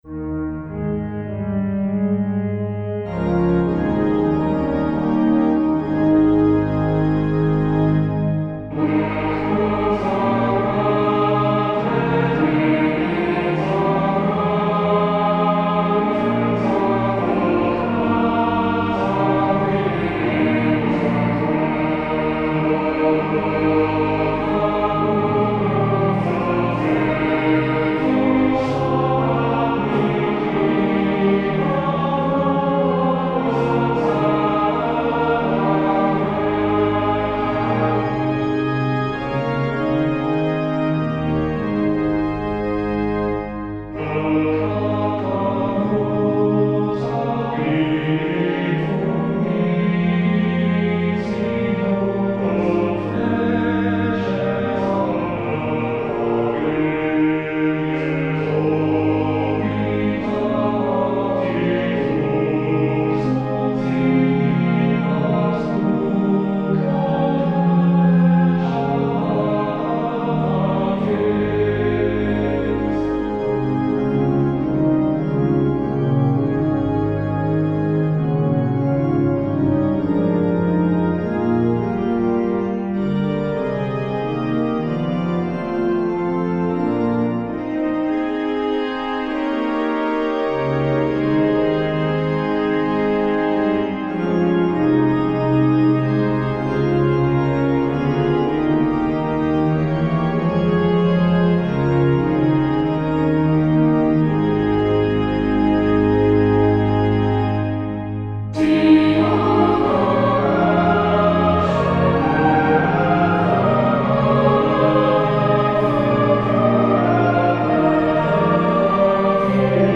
Free hymn descants with organ harmonizations and audio demos; many descants have extended (concertato) organ and choral parts.
|| Audio: intro (four bars, ad lib) | hymnal verse | free harmonization (Wm. Gardiner, 1815) | organ bridge (ad lib) | descant and harmonization Free score.